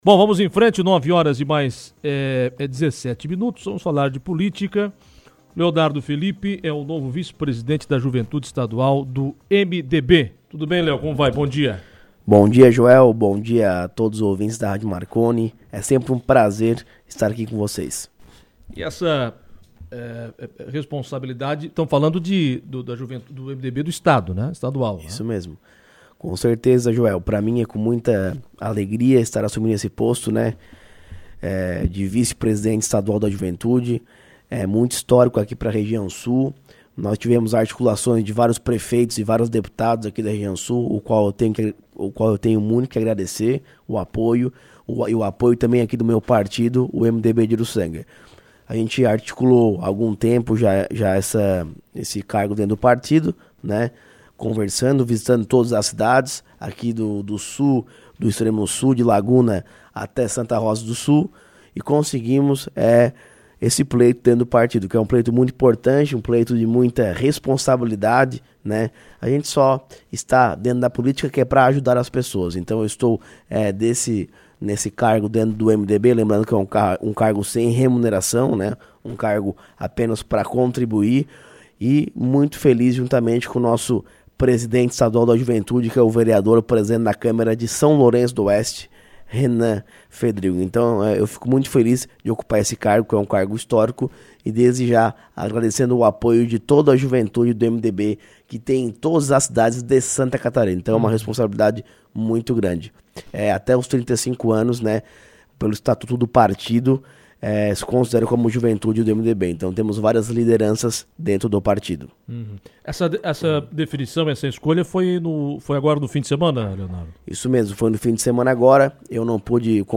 O assunto foi destaque em entrevista.